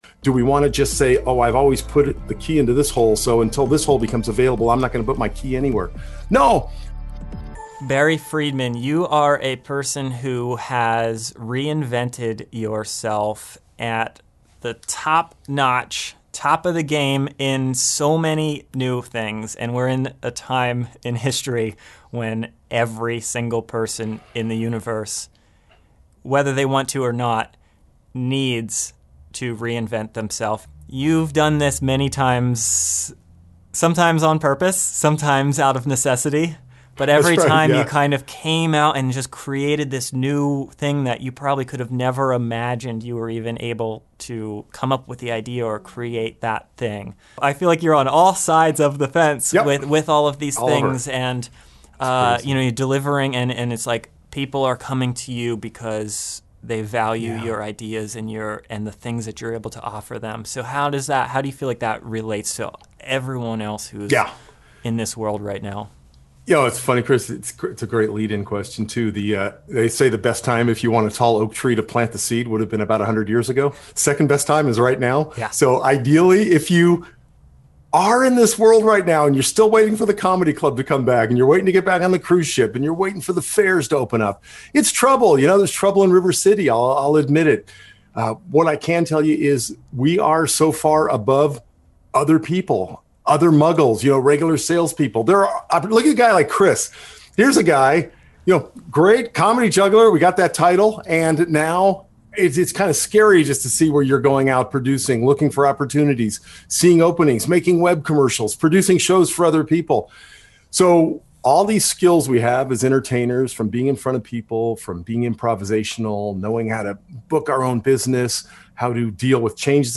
A short conversation on innovation mindset